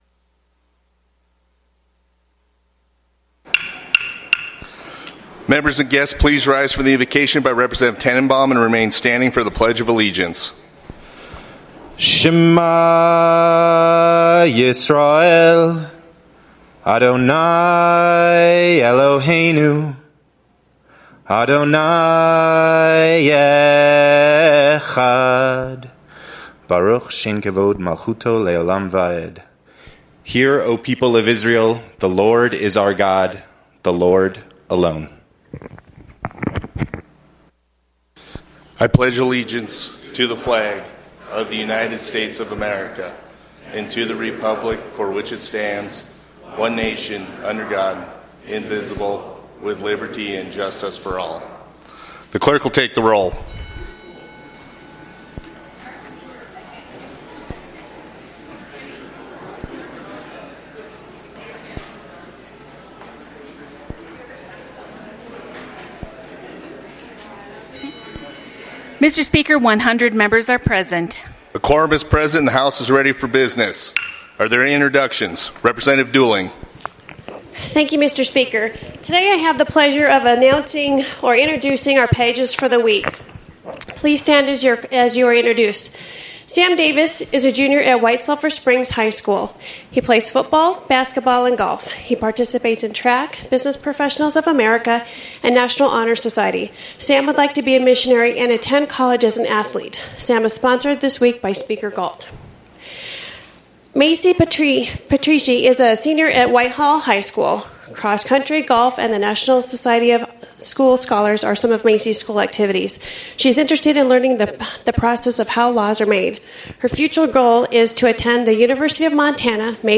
House Floor Session